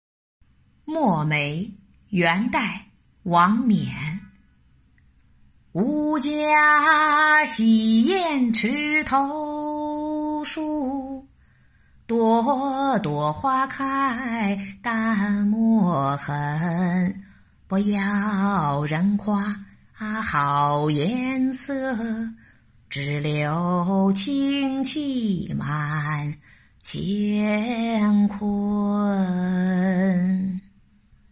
墨梅—古诗吟诵